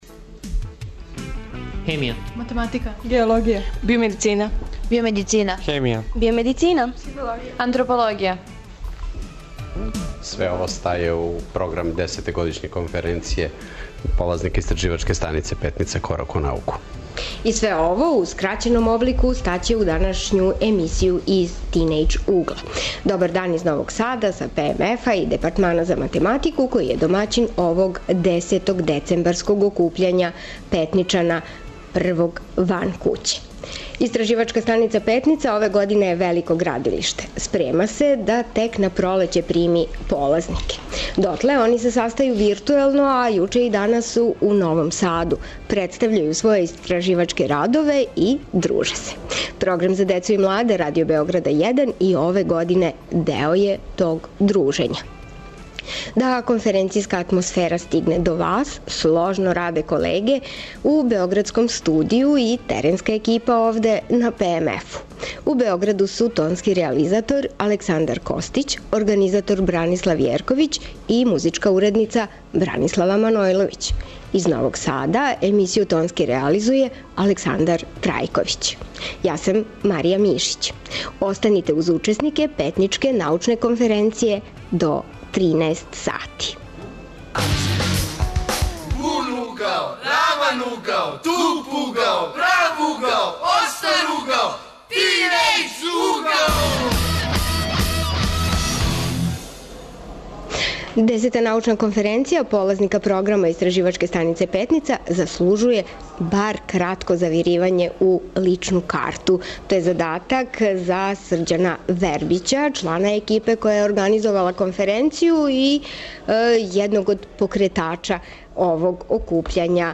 Емисију емитујемо са ПМФ-а у Новом Саду, где се одржава 10. Петничка конференција.
Око 60 младих представиће своје истраживачке радове настале током ове године усменом и постер презентацијом. Они ће бити и гости наше емисије.